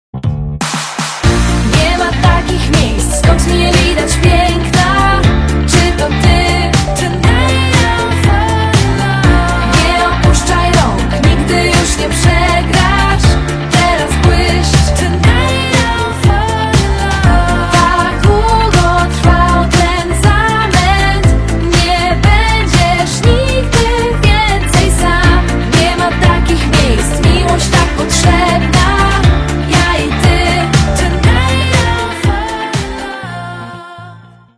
Dzwonki na telefon
Kategorie POP